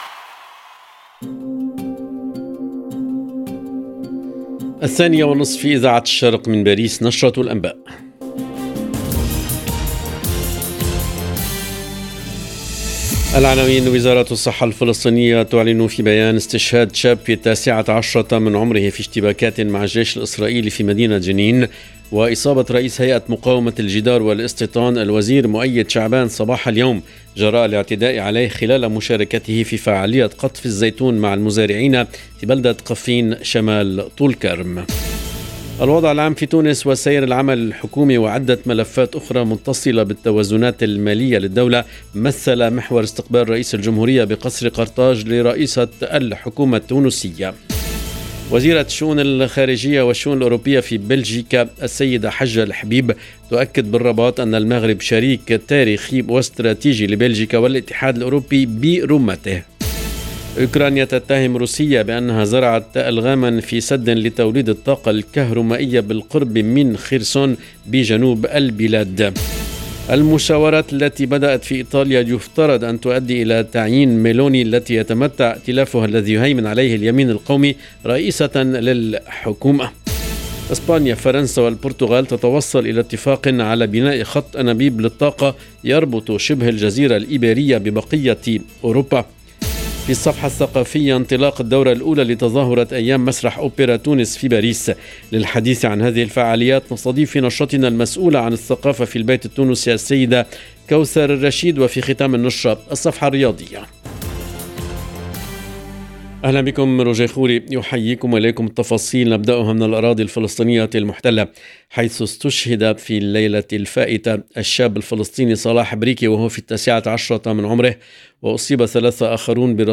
LE JOURNAL EN LANGUE ARABE DE LA MI-JOURNEE DU 21/10/22